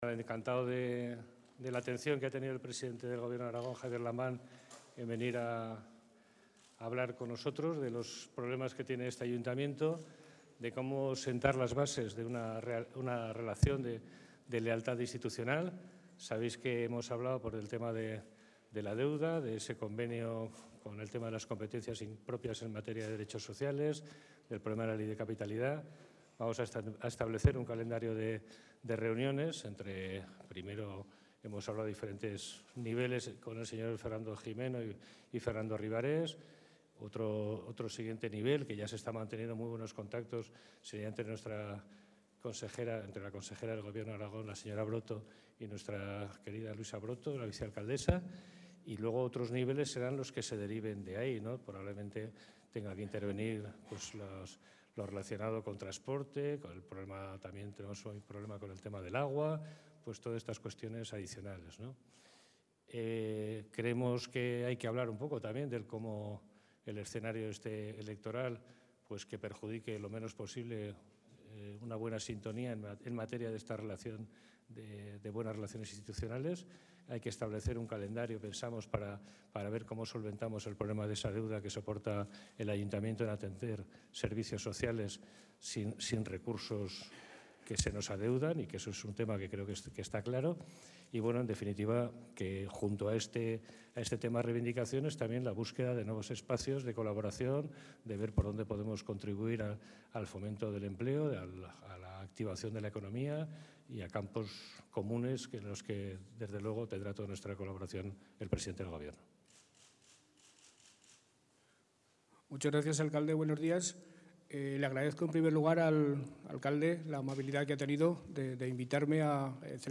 Escucha aquí el AUDIO COMPLETO de la reunión mantenida entre el Alcalde de Zaragoza, Pedro Santisteve, y el presidente del Gobierno de Aragón, Javier Lambán